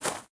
step_gravel.ogg